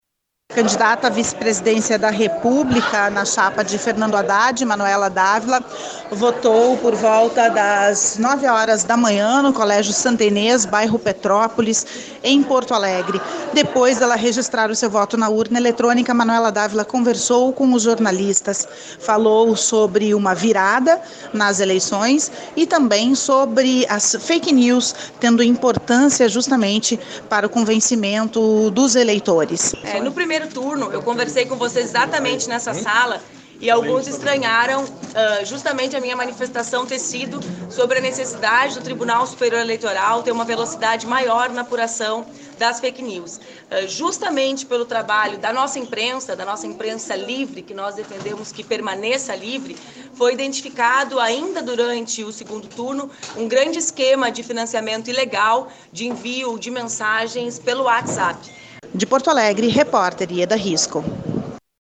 RadioAgência Senado